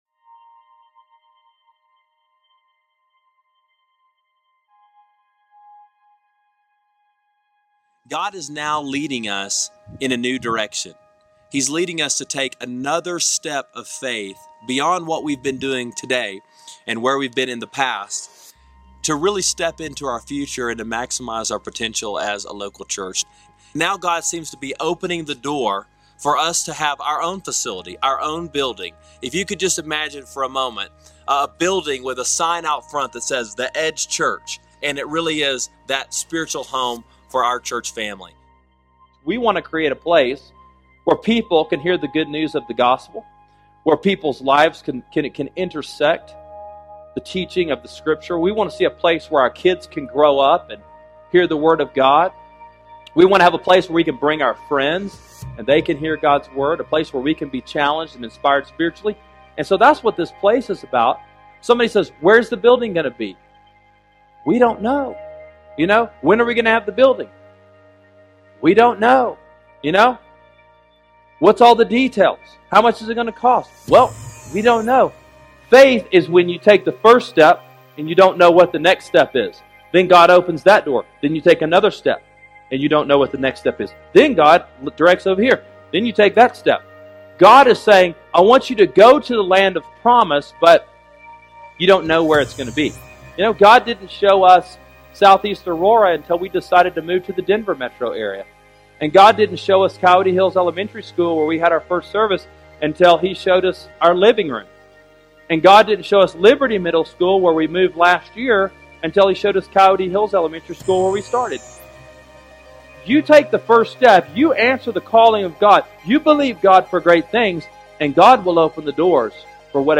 God Will Provide: Ephesians 3:20, Genesis 22:13-14 – Sermon Sidekick